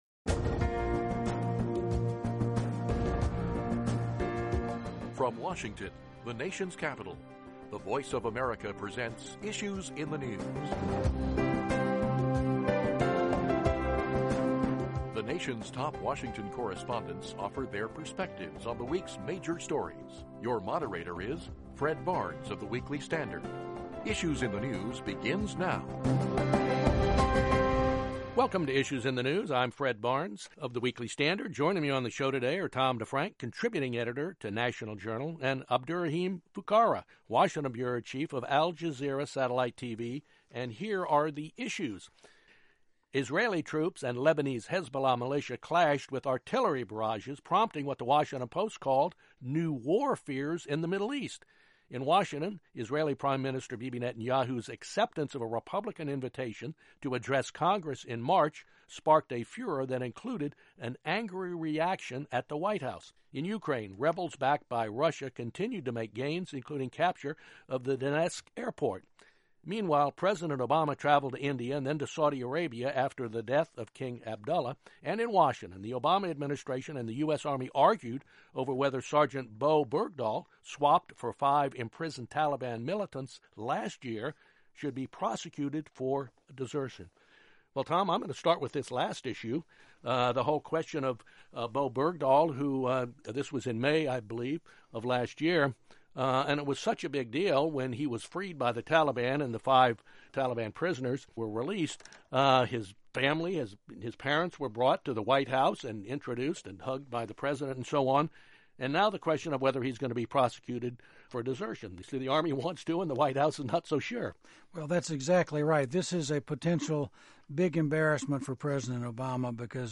Moderator Fred Barnes